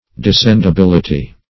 Descendibility \De*scend`i*bil"i*ty\, n.